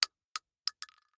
shotgun_generic_5.ogg